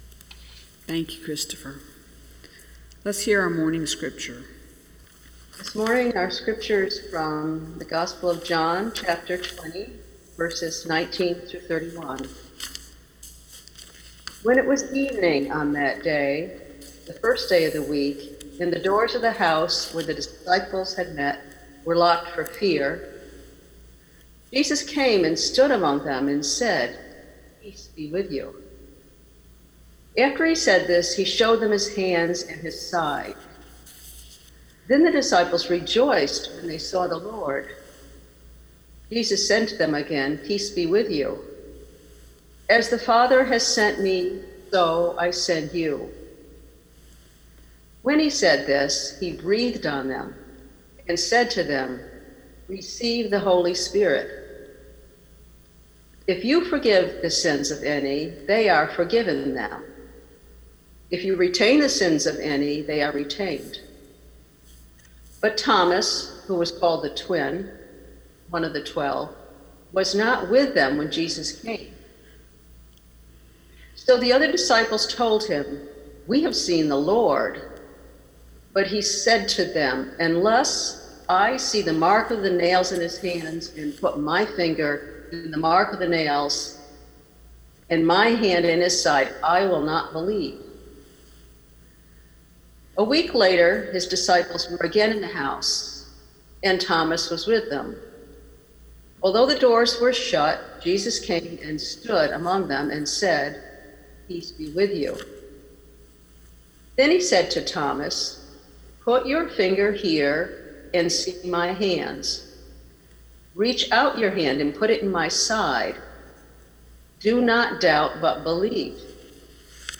Recorded Sermons - The First Baptist Church In Ithaca
Simply click on the date and title below to hear an audio recording of that week’s Scripture and meditation.